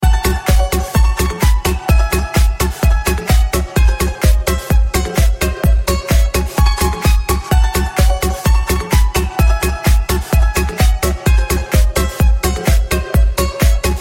balalaika_24767.mp3